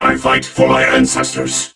robo_bo_start_vo_02.ogg